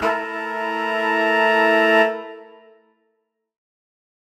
UC_HornSwell_Bmin9.wav